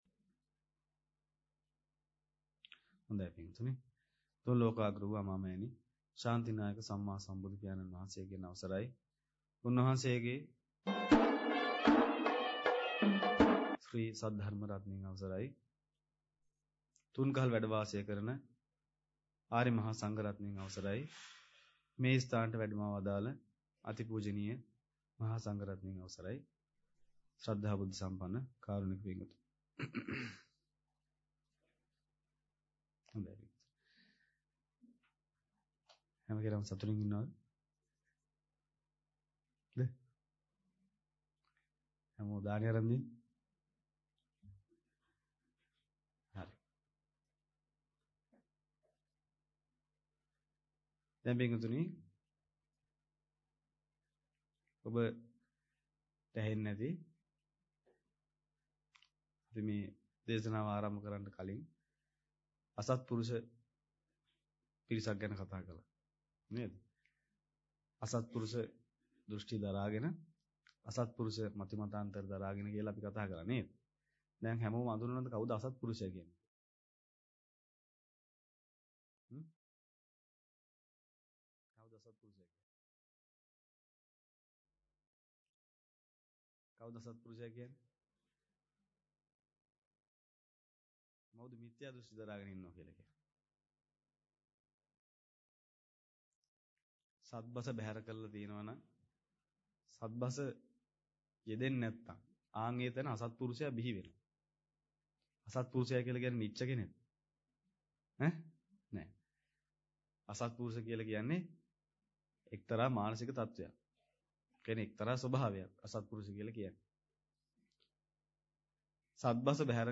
Sermon | JETHAVANARAMA